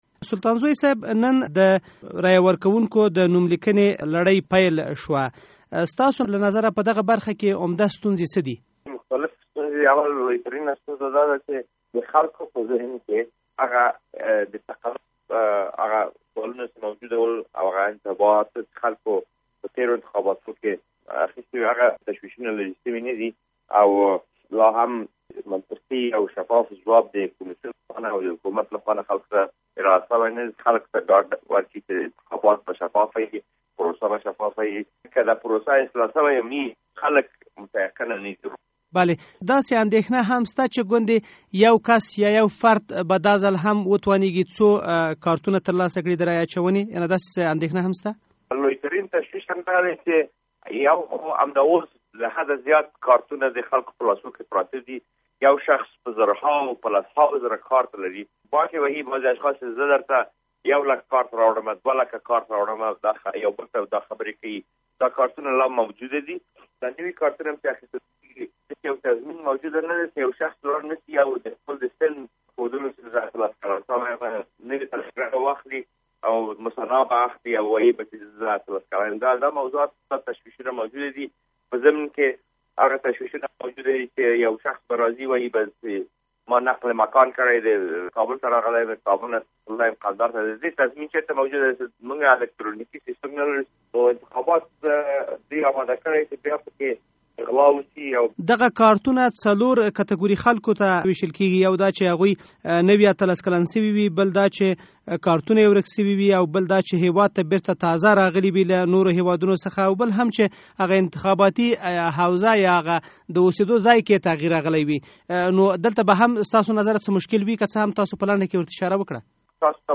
له داود سلطانزوی سره مرکه